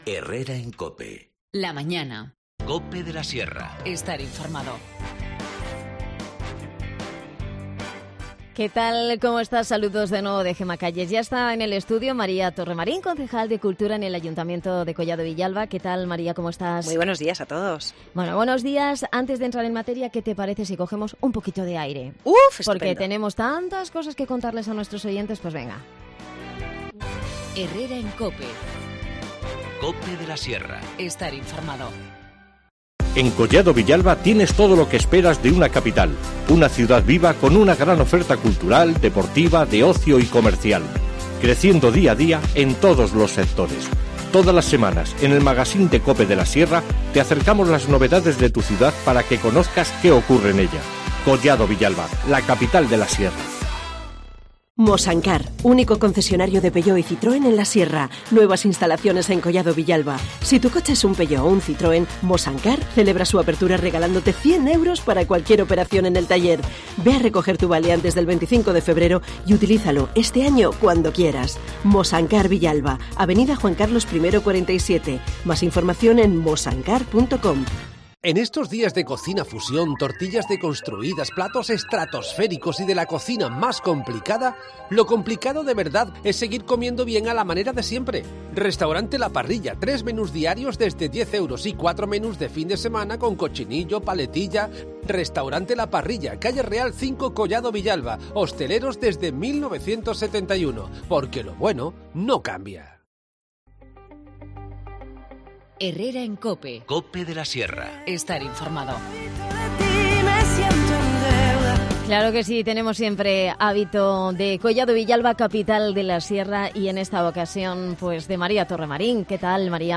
Redacción digital Madrid - Publicado el 21 feb 2019, 13:13 - Actualizado 15 mar 2023, 23:31 1 min lectura Descargar Facebook Twitter Whatsapp Telegram Enviar por email Copiar enlace Hoy en Collado Villalba, Capital de La Sierra, la Casa de Andalucía es protagonista. Nos da los detalles María Torremarín, concejal de cultura en el municipio